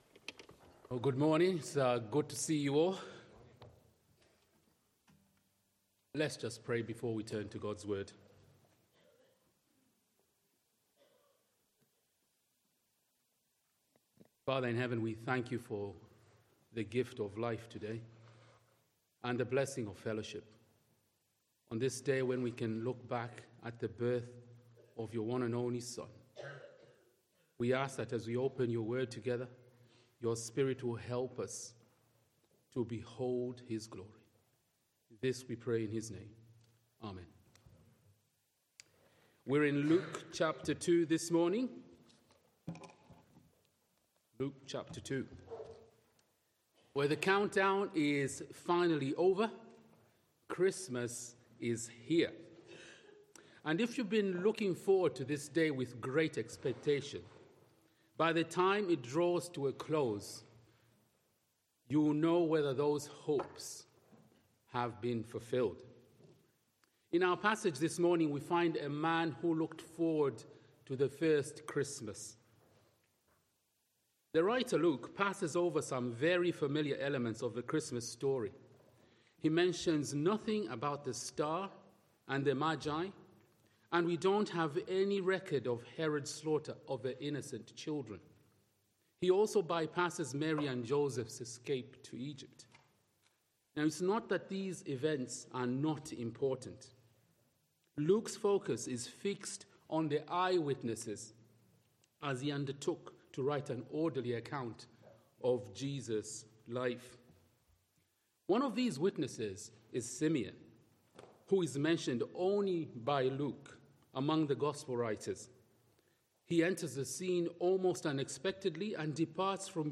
From Series: "Other Sermons"